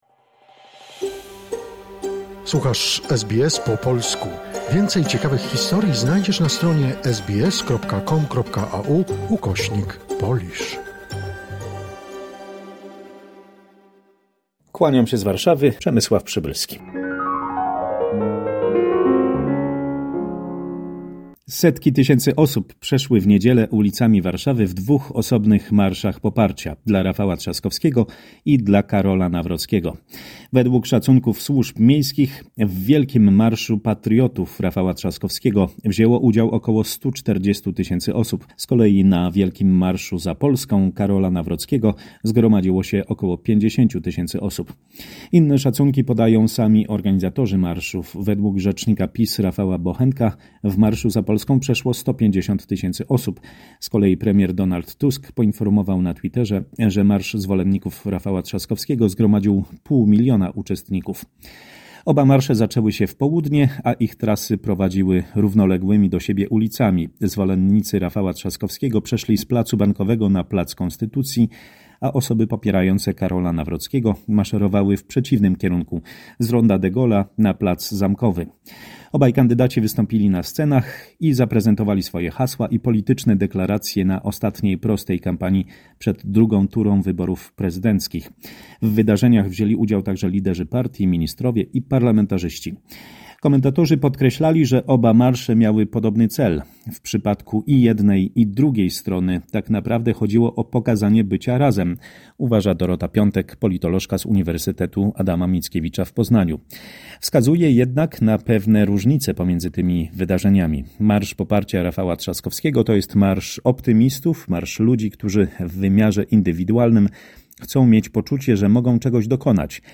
W dzisiejszej korespondencji z Polski o warszawskich marszach poparcia dla kandydatów na prezydenta.